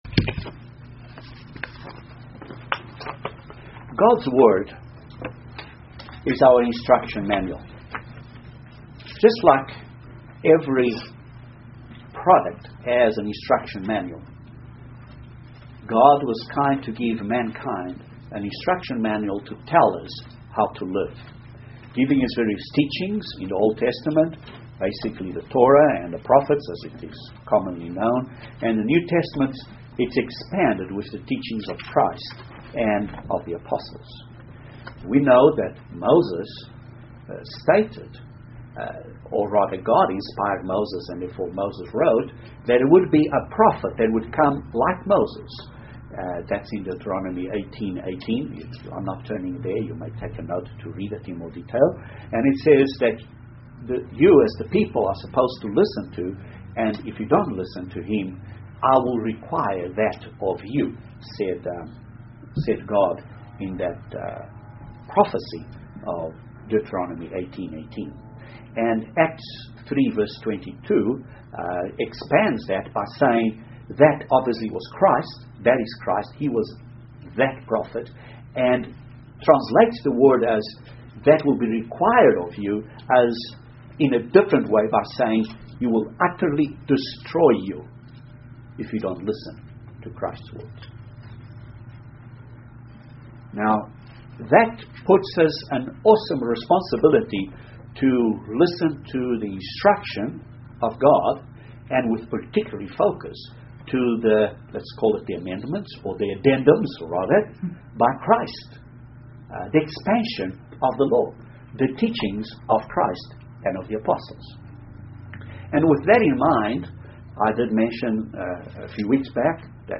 This sermon studies a number of facets of this attitude, such as going into the house of mourning, being sober, sighing and crying for the sins of the world, and others. Then it identifies things we need to do to further develop this attitude and what it should lead to, as far as ourselves, and in our conduct towards others.